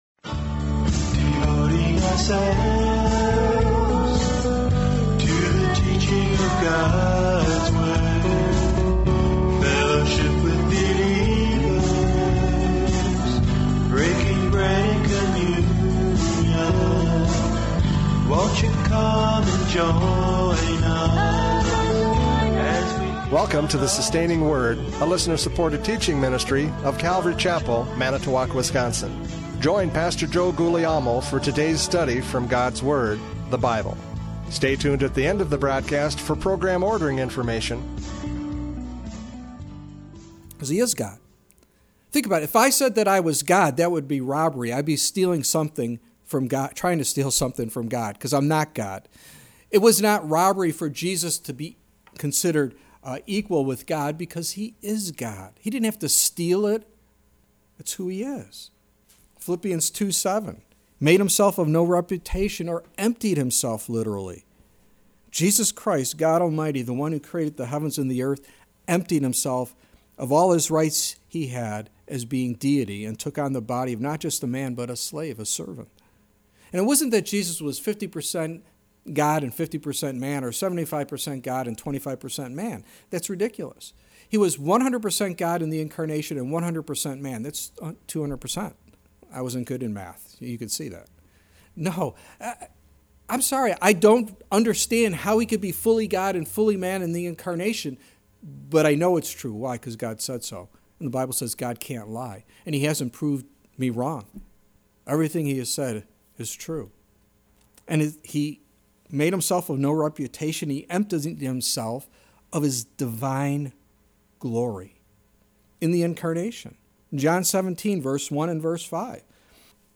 John 5:19-21 Service Type: Radio Programs « John 5:19-21 Equality in Power!